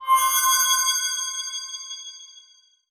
magic_shinny_high_tone_01.wav